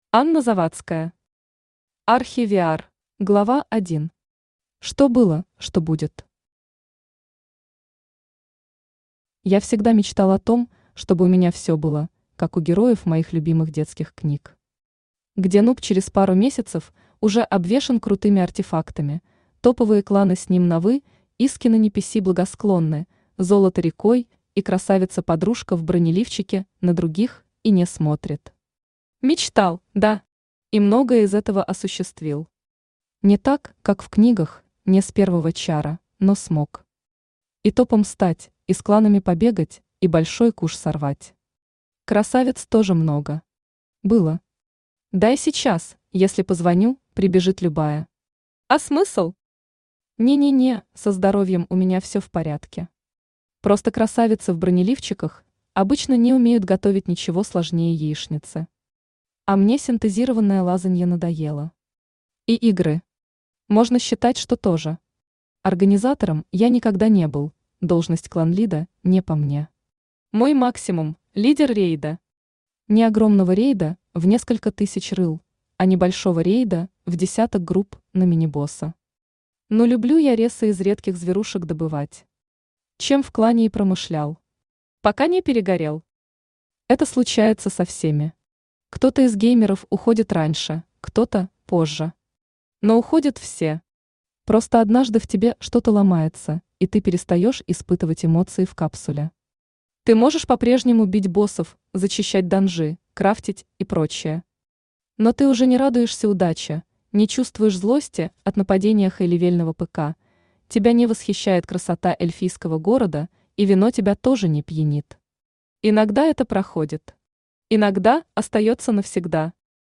Aудиокнига Архи-VR Автор Анна Владимировна Завадская Читает аудиокнигу Авточтец ЛитРес.